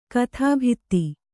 ♪ kathābhitti